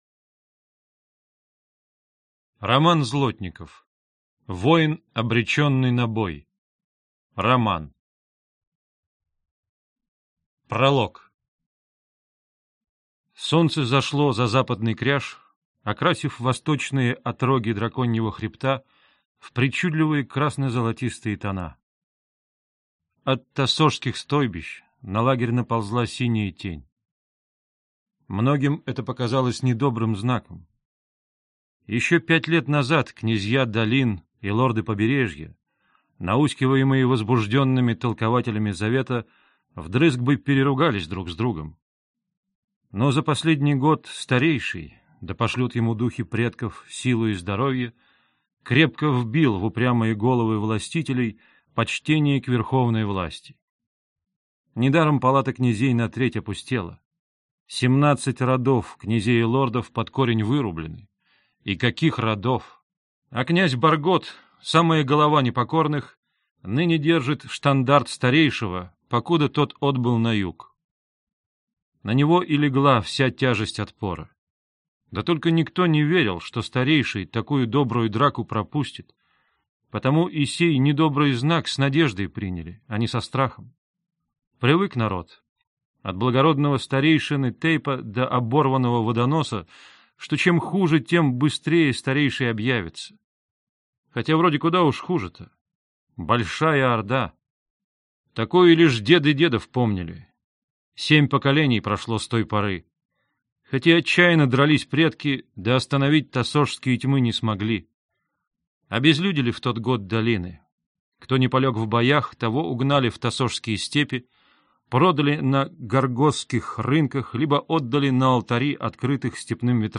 Аудиокнига Воин. Обреченный на бой | Библиотека аудиокниг